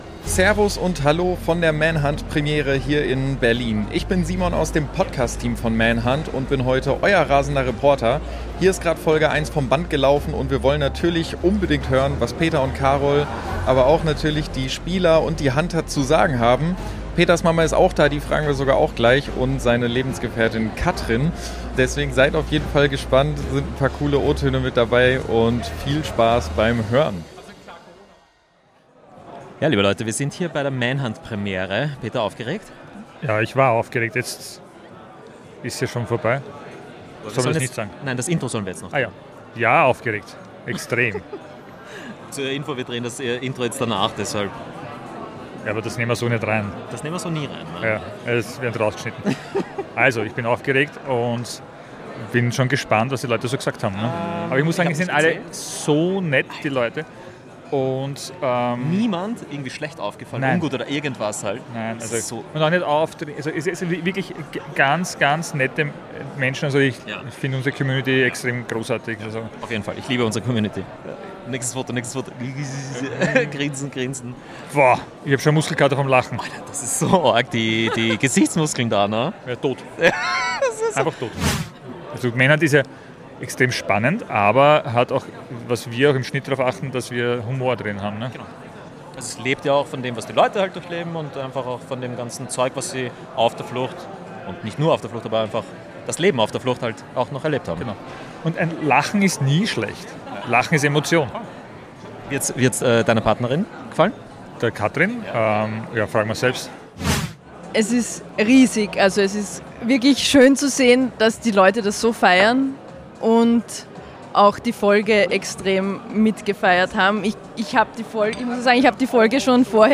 Denn am Samstag, den 25.10.25, lief in Berlin die 1. Folge von Staffel 3 auf der exklusiven Premiere im Colosseum. Wir haben einige Spieler und Hunter gefragt, wie sie die Folge fanden und was sie überrascht hat.